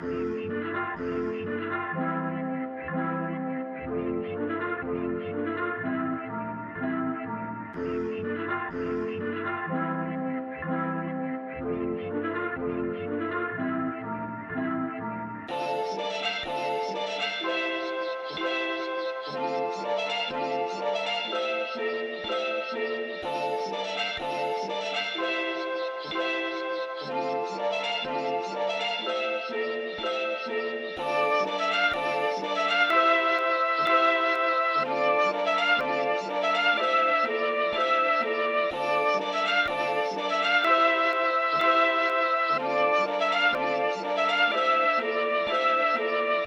Teck-maria maria_124bpm.wav